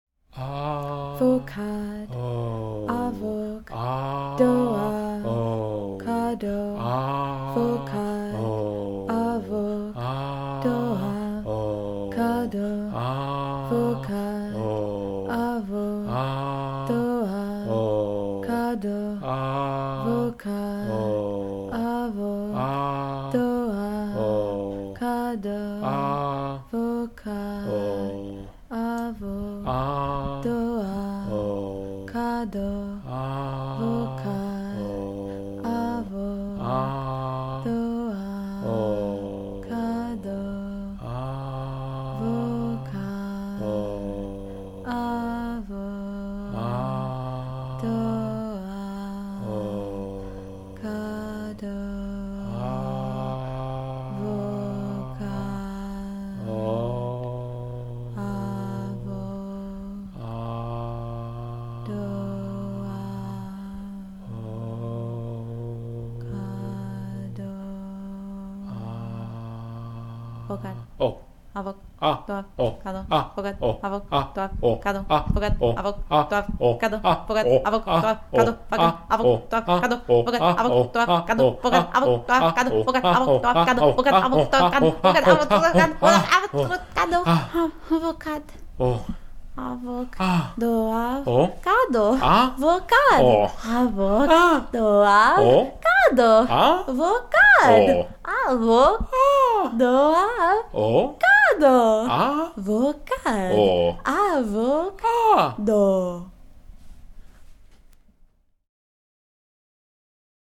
spoken noise duo